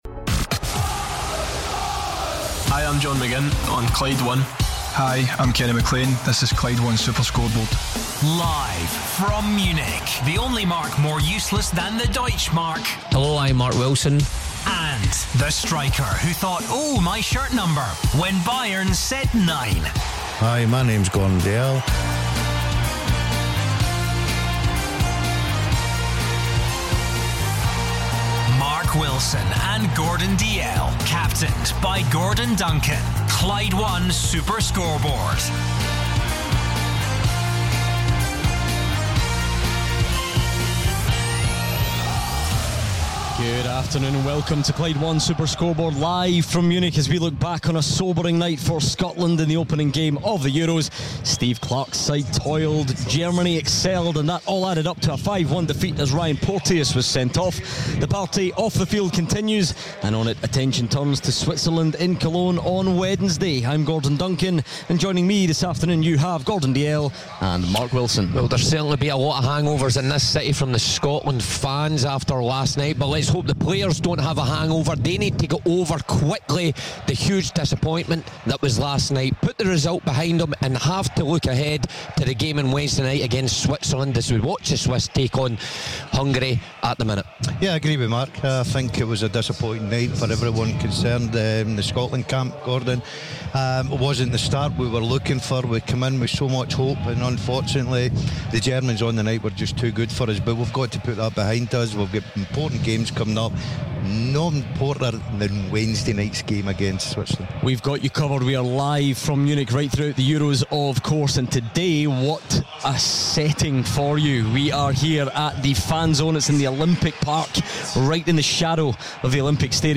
LIVE from Palm Beach, Stuttgart